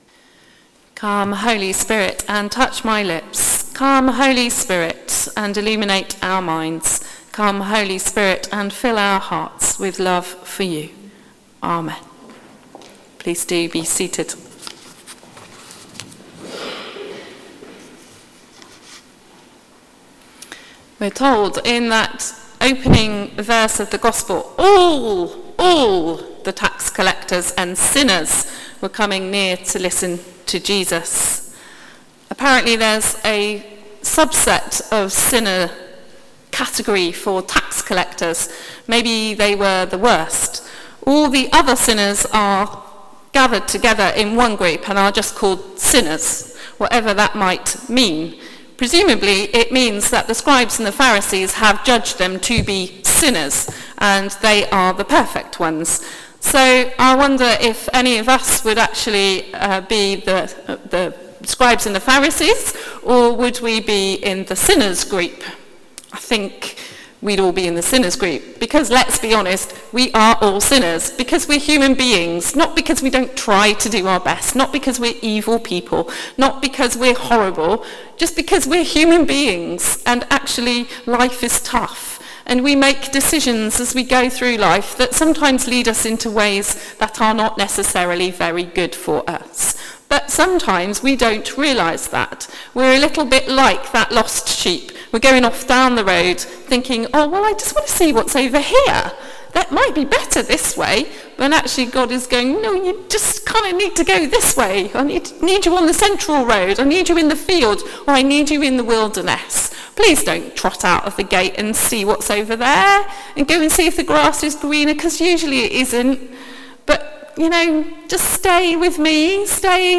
Sermon: Heaven rejoices over you | St Paul + St Stephen Gloucester